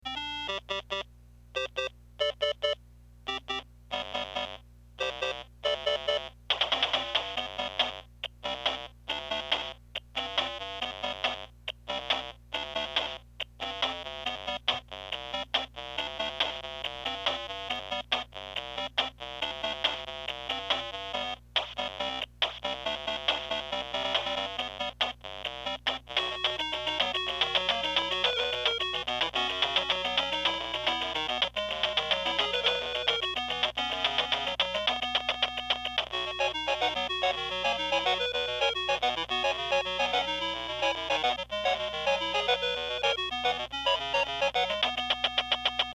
All Atari Lynx II's used for these speaker tests, had there volume control wheel turned to Maximum sound volume out and the recording microphone was placed the same distance from each Lynx II built in speaker.  The Atari Game cartridge used to run the sound tests is the Lynx California Games start up screen and then the second California 4 game selection screen.
New Atari Lynx II Speaker.MP3
Stock Lynx II speaker 3.mp3